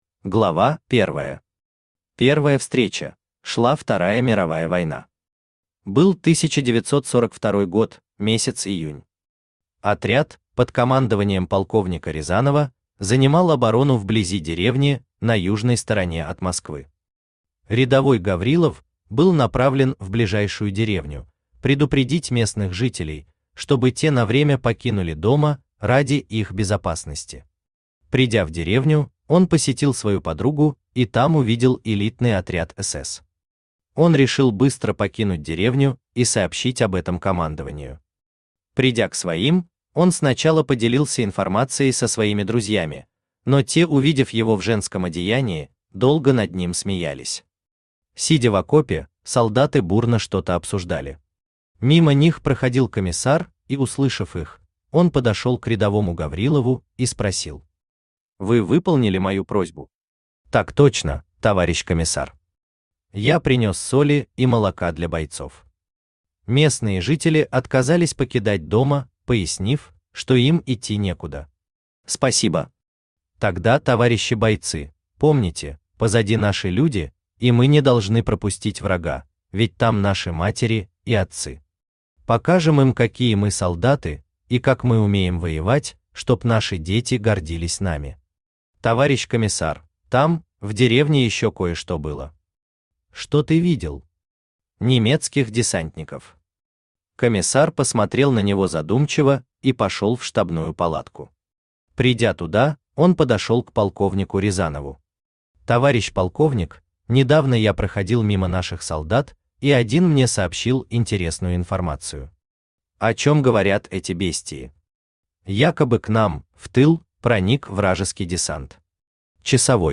Аудиокнига Пришельцы убийцы | Библиотека аудиокниг
Aудиокнига Пришельцы убийцы Автор ALEX 560 Читает аудиокнигу Авточтец ЛитРес.